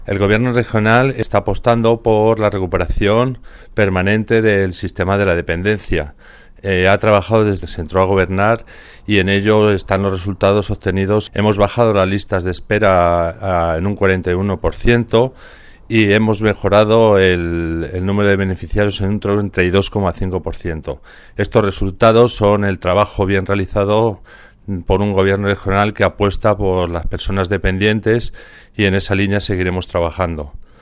El director provincial de Bienestar Social de Guadalajara habla de la mejora del sistema de atención a la dependencia desde que Emiliano García-Page es presidente.